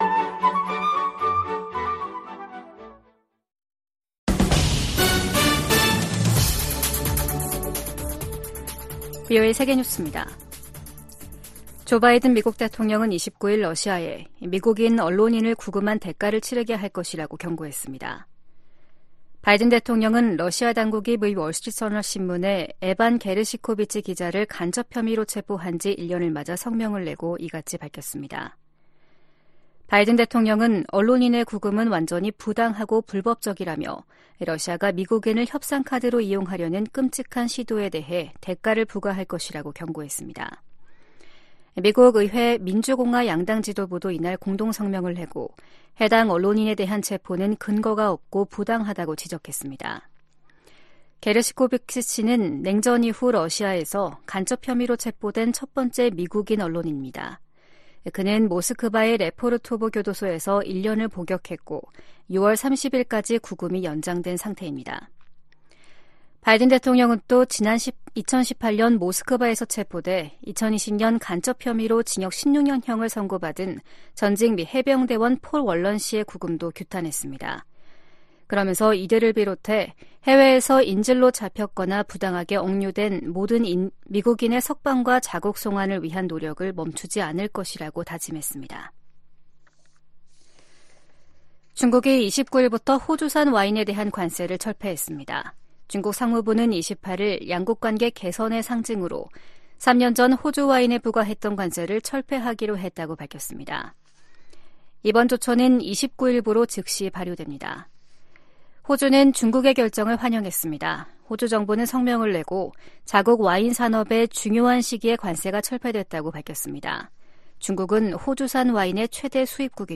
VOA 한국어 아침 뉴스 프로그램 '워싱턴 뉴스 광장' 2024년 3월 30일 방송입니다. 유엔 안보리 대북 결의 이행을 감시하는 전문가패널의 임기 연장을 위한 결의안 채택이 러시아의 거부권 행사로 무산됐습니다. 이에 미국과 한국·일본 등은 러시아를 강하게 비판했습니다.